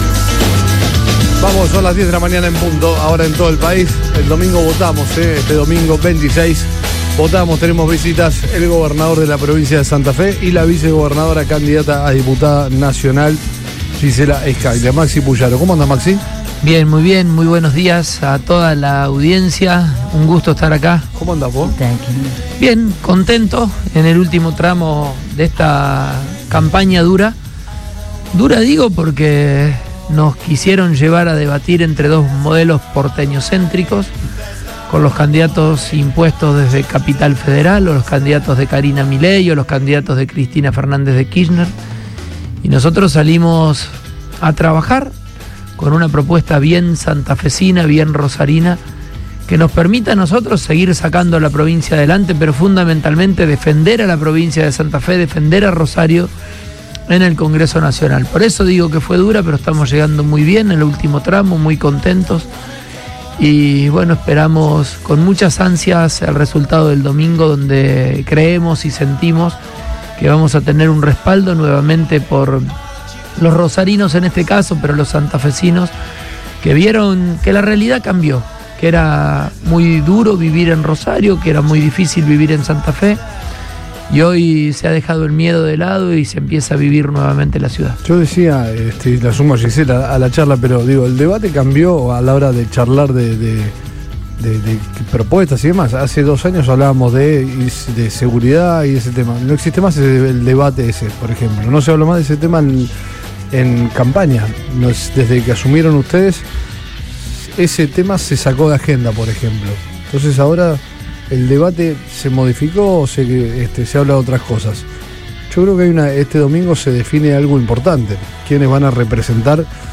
El gobernador de la provincia, Maximiliano Pullaro, visitó los estudios de Radio Boing junto con la vicegobernadora y candidata a diputada nacional…